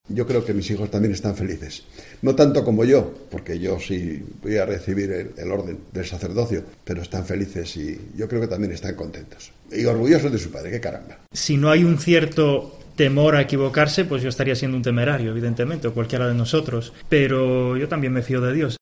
Hablan los nuevos sacerdotes de la Iglesia asturiana: "Estamos felices"